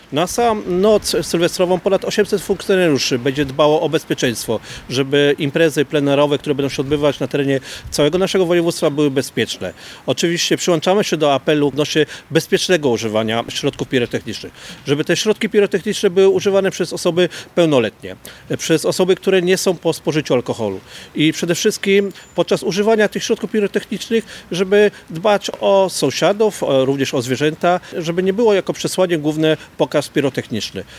Prowadzone będą wzmożone kontrole prędkości, sprawdzana będzie również trzeźwość kierujących. Informuje insp. Robert Sielski – Zastępca Komendanta Wojewódzkiego Policji